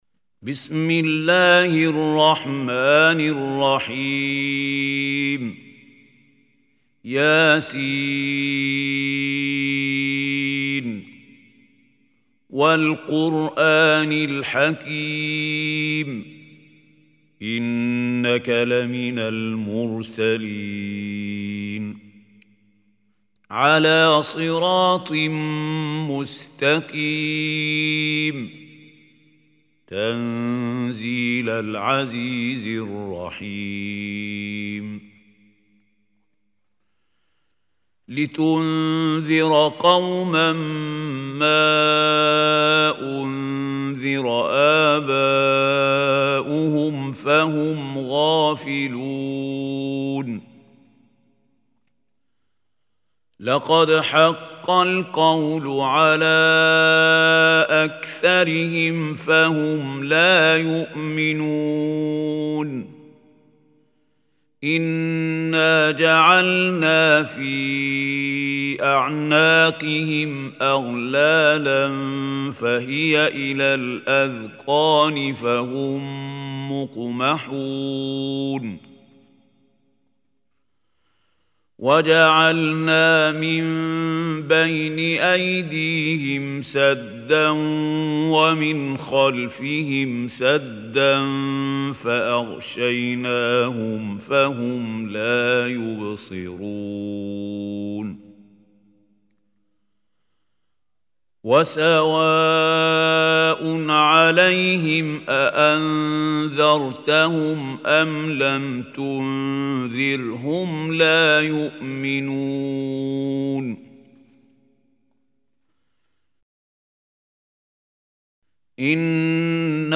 Surah Yaseen Audio Recitation by Khalil Hussary
Surah Yaseen is 36 surah of Holy Quran. Listen or play online mp3 tilawat / recitation in Arabic in the beautiful voice of Mahmoud Khalil Al Hussary.